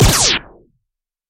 Звуки пиу
Звук стрельбы - эхо выстрелов